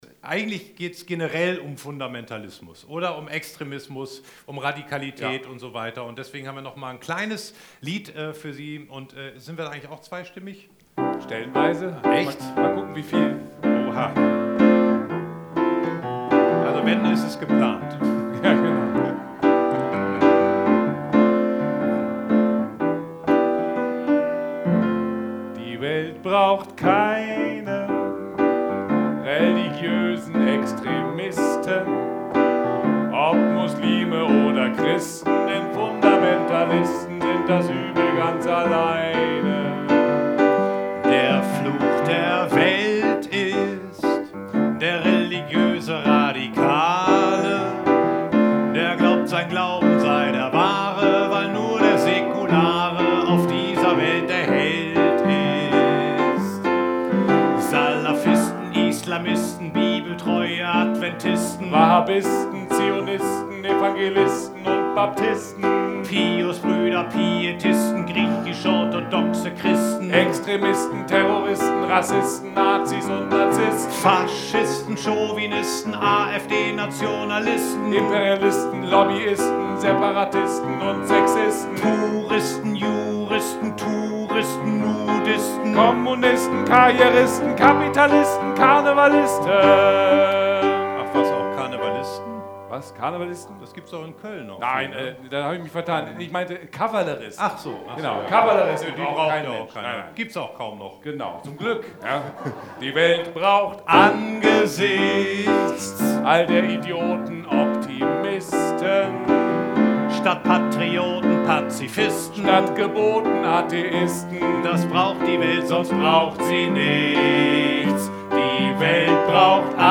Events, Live-Übertragungen
Es wurde musiziert, Geschichten vorgelesen und Gedichte vorgetragen.
BENEFIZ-COMEDY-MIXED-SHOW